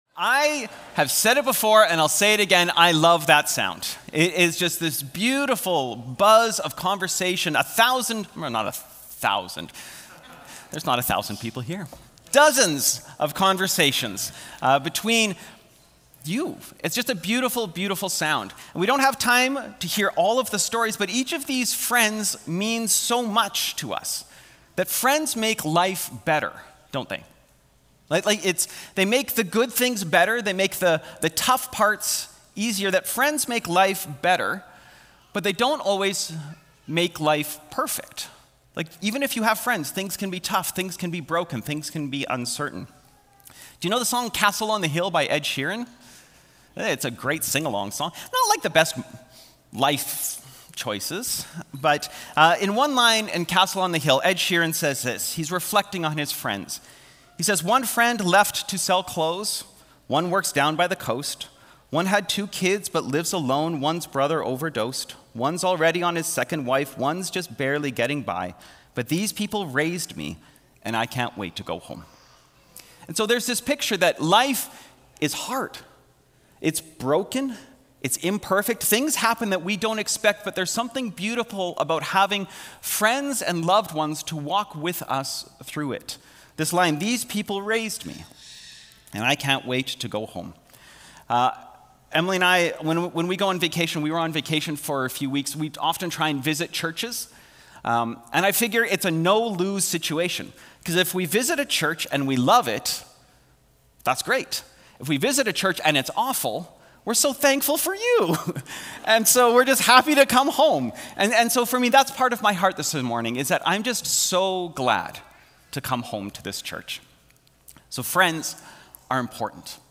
Aldergrove Sermons | North Langley Community Church